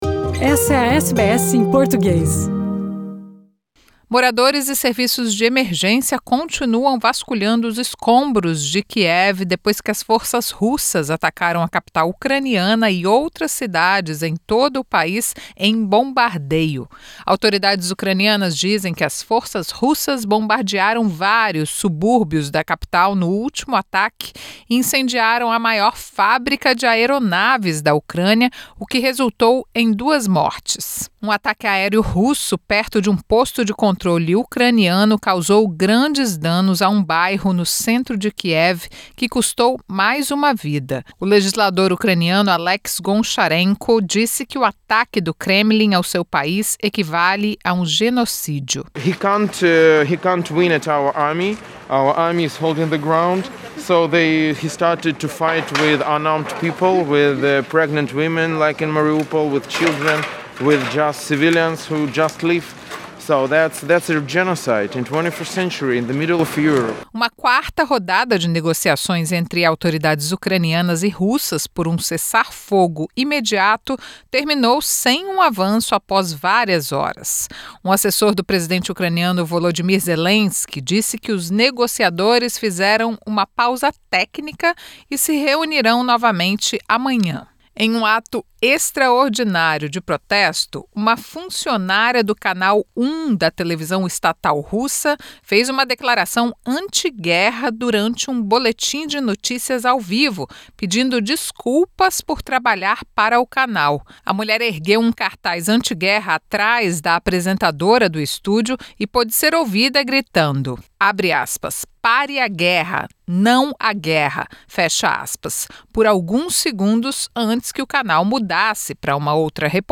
Oposição diz que governo australiano não está fazendo o suficiente para aliviar as pressões no custo de vida diante de impasse no imposto sobre combustível. Escassez de profissionais na Austrália é a maior desde 1974. As notícias da Austrália e do mundo da Rádio SBS para esta terça-feira.